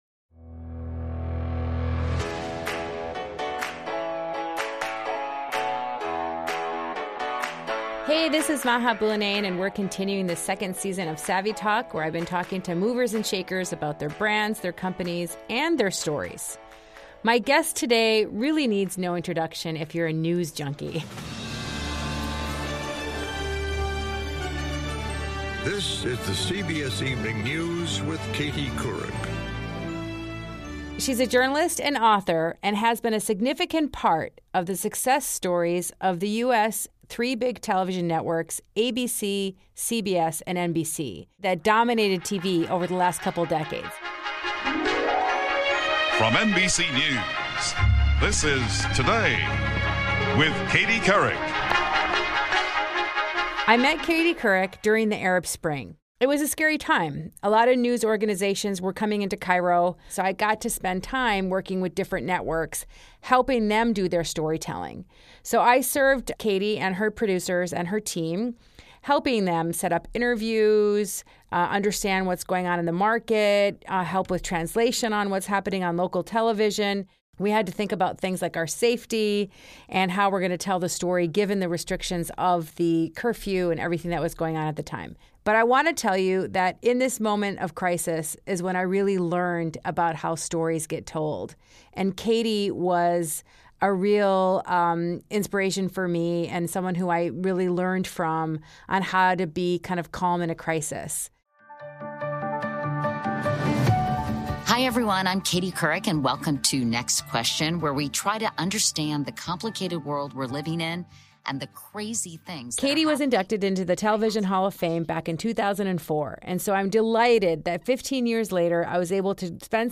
She's a journalist, an author and has been a significant part of the success stories of the US' three major TV networks that dominated the 80s and 90s--ABC, CBS & NBC, not to mention her work with CNN & Yahoo News as well. 15 years after being inducted into the TV Hall of Fame in 2004, we sat down at her home in the Hamptons to find out what she's been upto since she left the networks.